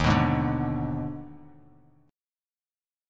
D#M7sus4#5 Chord